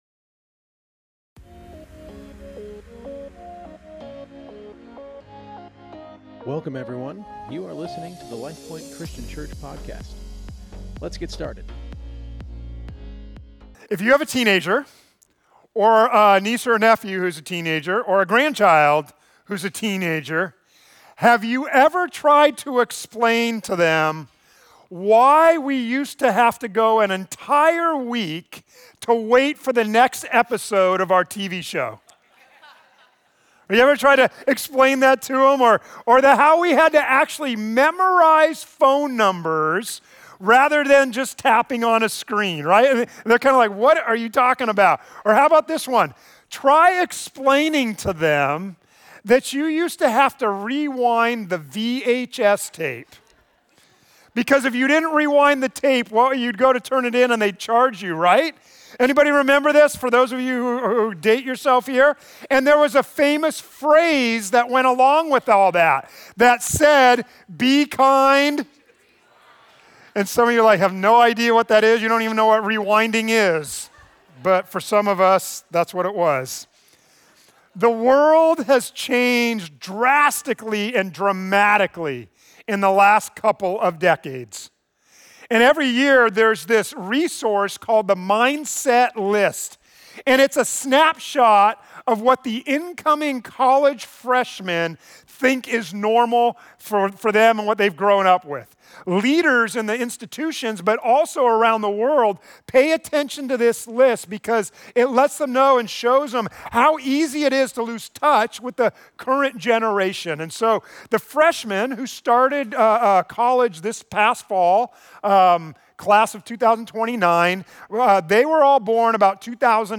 Subscribe to receive notification when new sermons are posted!